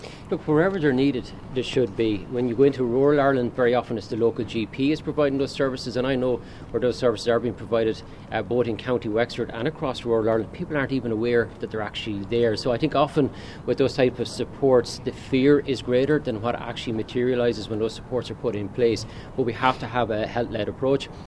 However, Minister Browne says safe injection sites are also needed outside of urban areas.